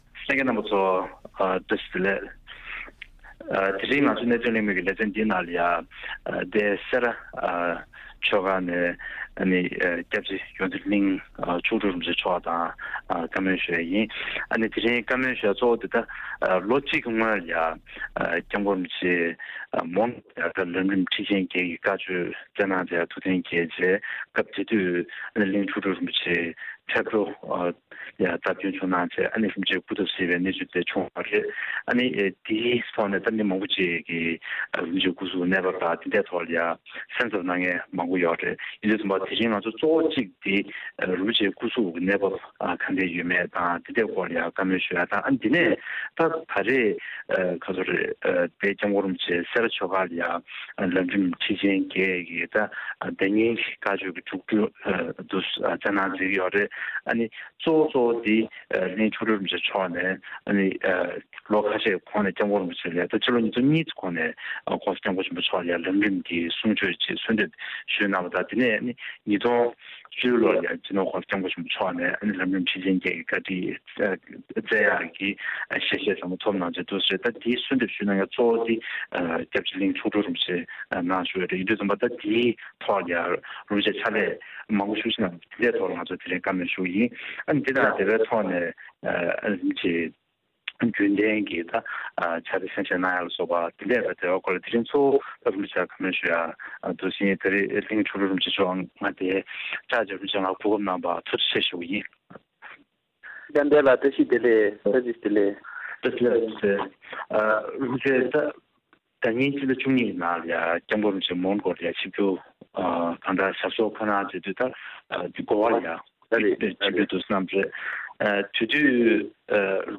གླིང་མཆོག་སྤྲུལ་དང་ཐད་ཀར་བཀའ་མོལ།